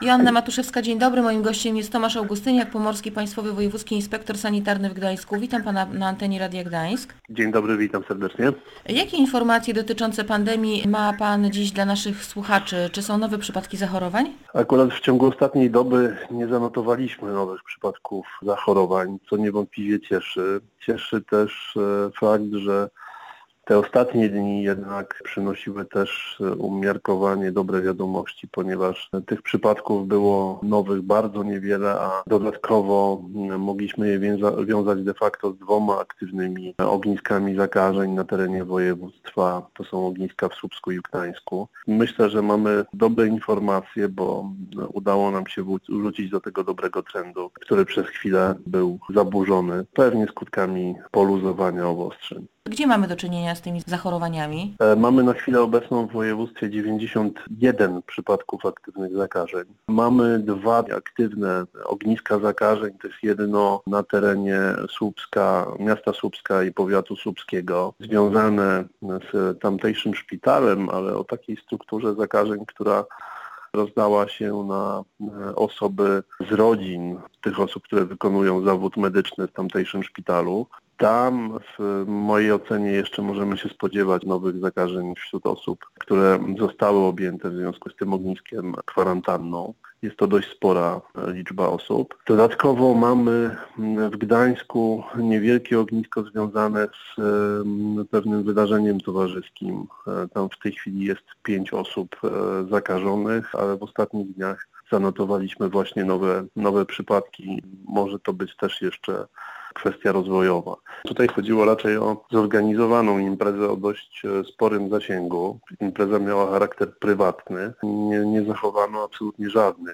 – Ognisko słupskie dotyczy rodzin pracowników szpitala i może się rozszerzać – mówił w Radiu Gdańsk Tomasz Augustyniak, Pomorski Państwowy Wojewódzki Inspektor Sanitarny.